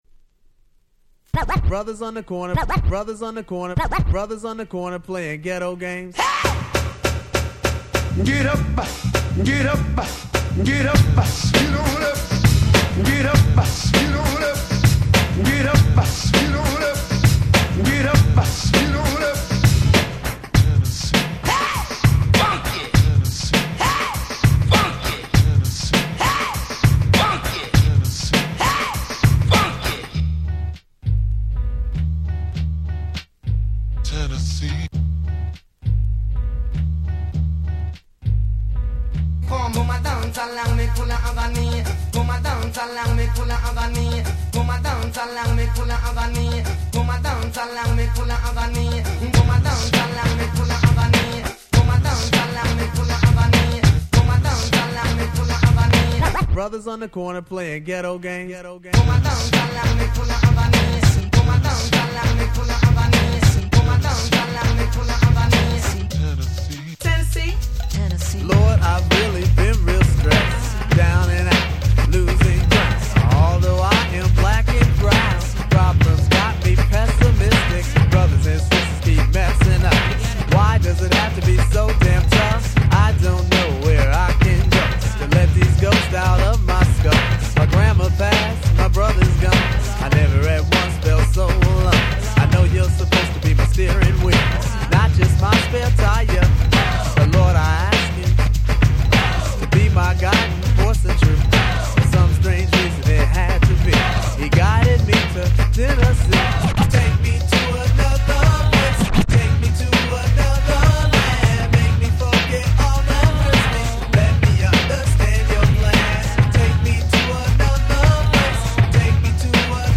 92' Super Hip Hop Classics !!
Boom Bap ブーンバップ